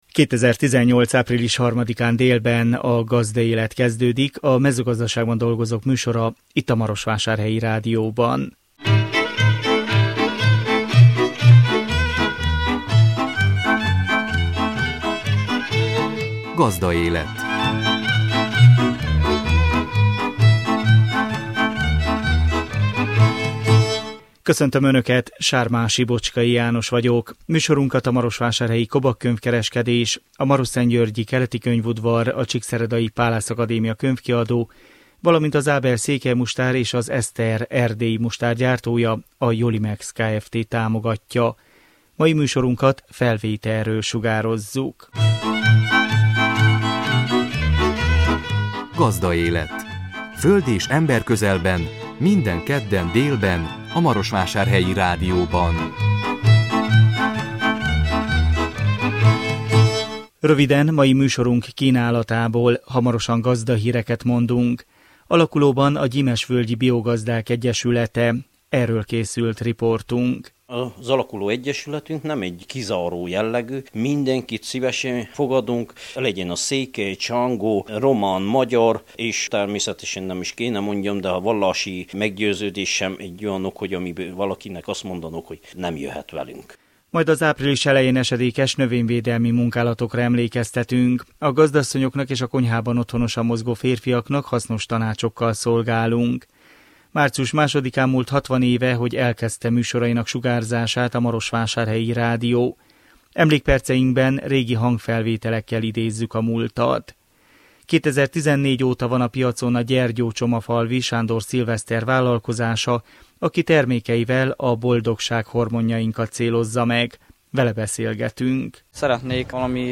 Emlékperceinkben régi hangfelvételekkel idézzük a múltat. 2014 óta […]